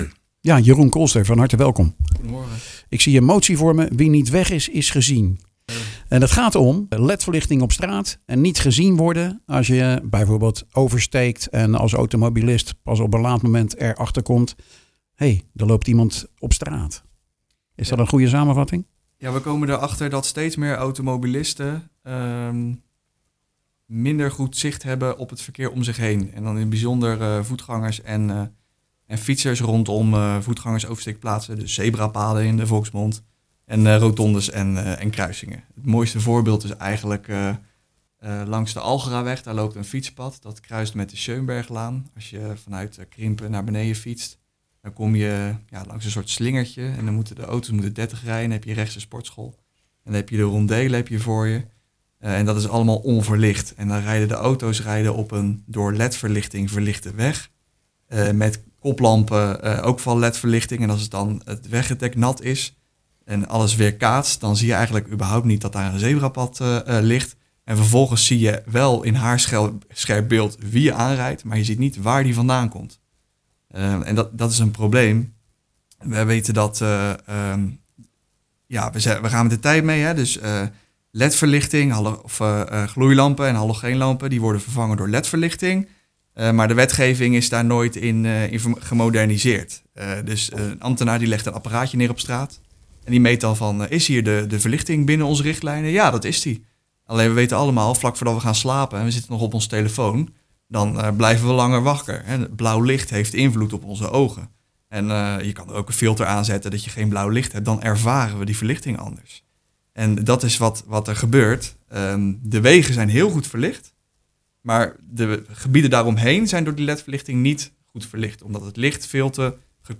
praat erover met VVD raadslid Jeroen Kolster.